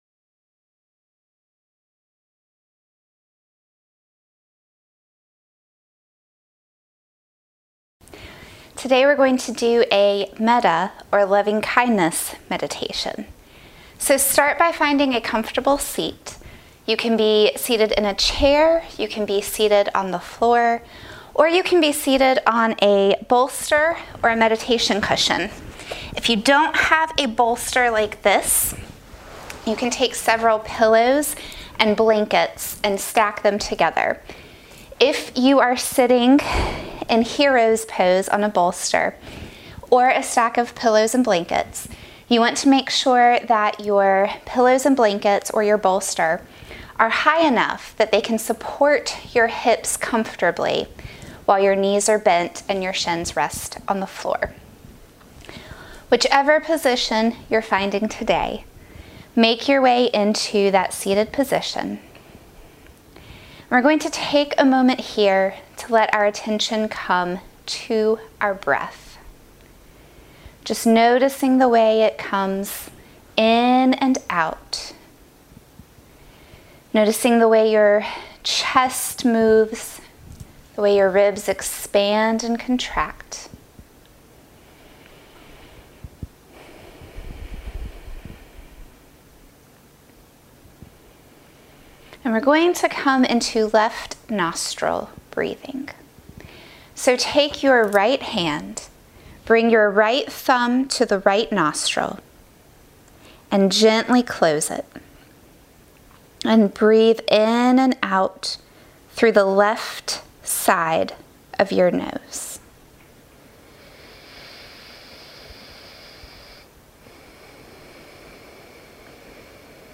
Metta Meditation
metta-meditation.mp3